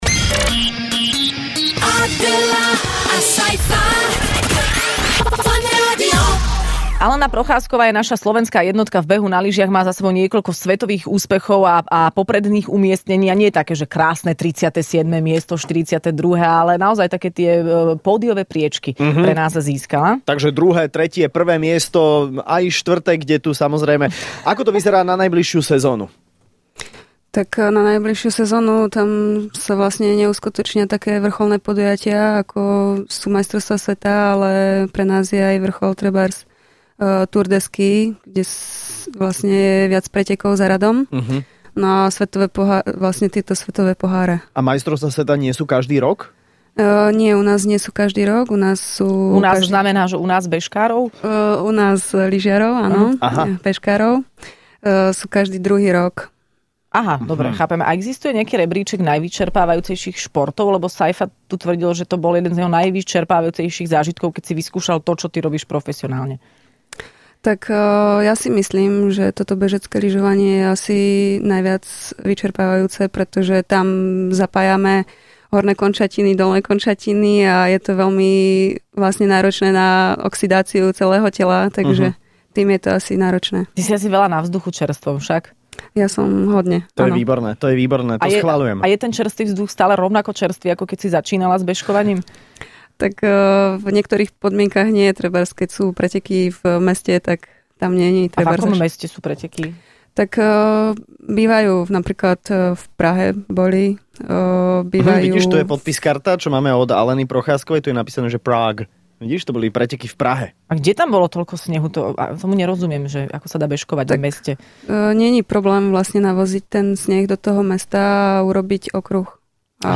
Hosťom v Rannej šou bola slovenská jednotka v behu na lyžiach - Alena Procházková